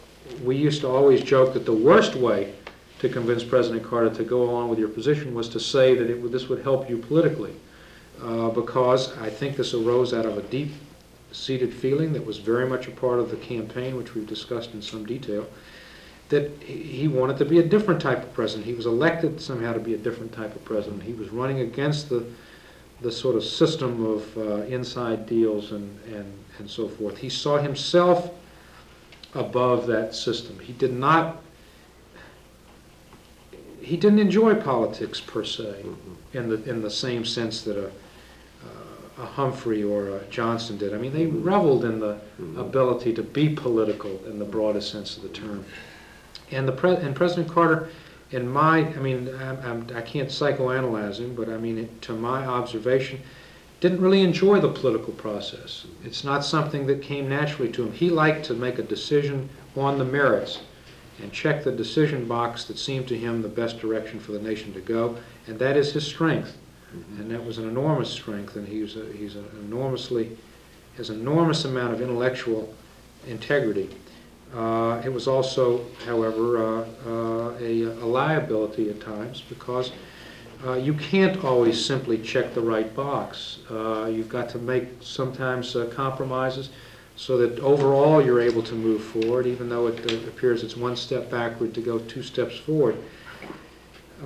'A Different Type of President' Photo: National Archives and Records Administration Stuart Eizenstat, assistant to the president for domestic affairs and policy, reflected on President Jimmy Carter’s relationship to politics and how it influenced his decision-making style. Date: January 29, 1982 Participants Stuart Eizenstat Associated Resources Stuart Eizenstat Oral History The Jimmy Carter Presidential Oral History Audio File Transcript